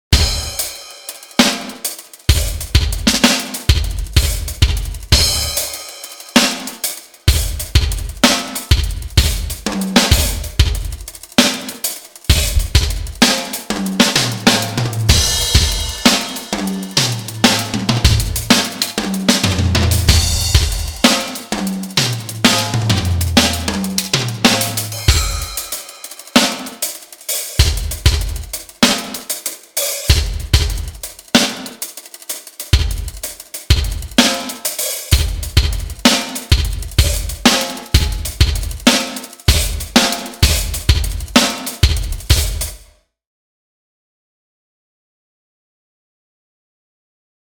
I pulled up some multitrack drum noodling & a verb bus comparing both the X-verb & Lexicon m300.
For the comparison I pulled up similar named presets on both the X-verb/ m300 & recorded the results @ 24/48.
verb-test-X-Brick-Wall-Rec-5467.mp3